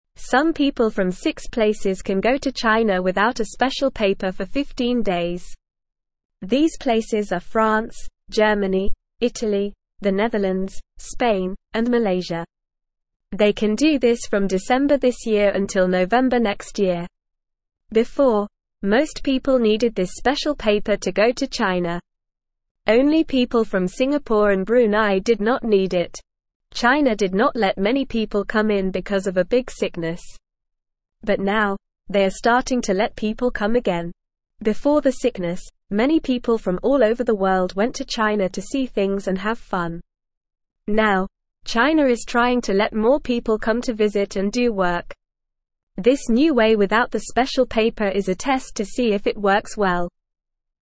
Normal
English-Newsroom-Beginner-NORMAL-Reading-China-Allows-Visitors-from-Six-Countries-Without-Special-Paper.mp3